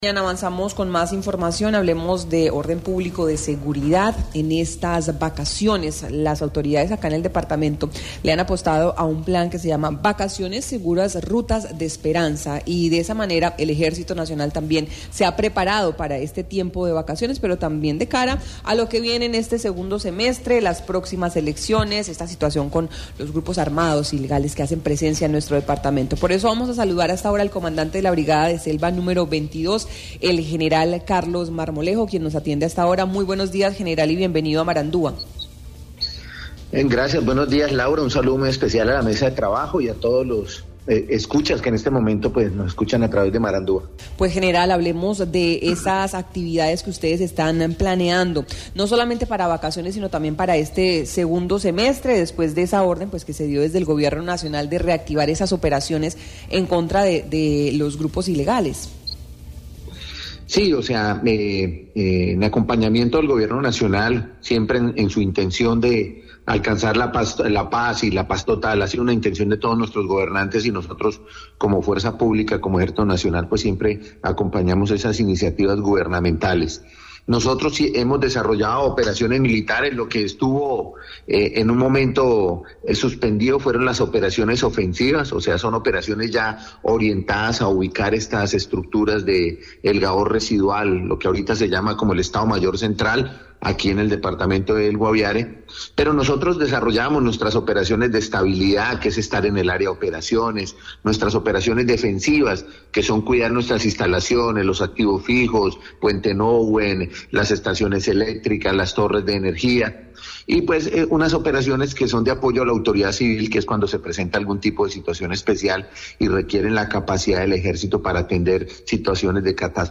Así lo dio a conocer en Marandua Noticias el Brigadier General Carlos Marmolejo, comandante de la Brigada 22 de selva, quien se refirió a esta campaña de vacaciones en la que se tiene como propósito alcanzar la paz total propuesta por el Gobierno Nacional.